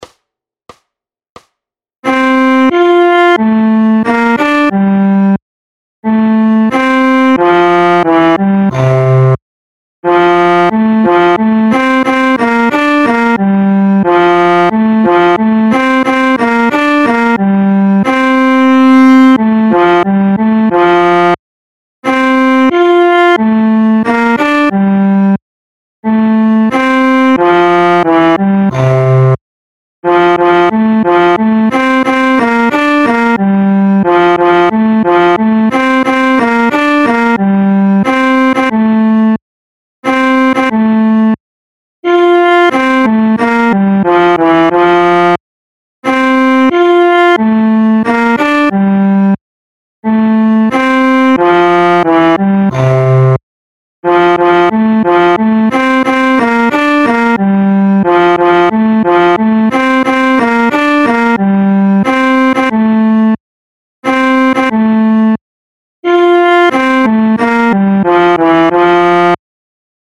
Noty na violoncello.
Hudební žánr Vánoční písně, koledy